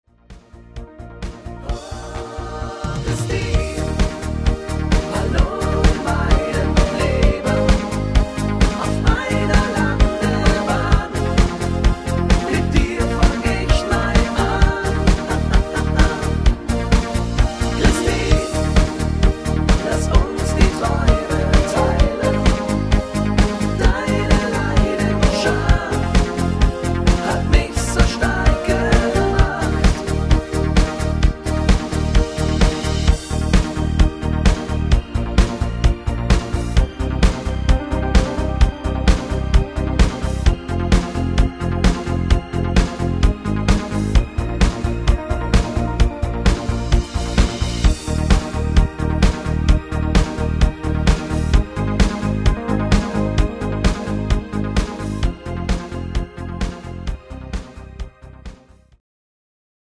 Rhythmus  Discofox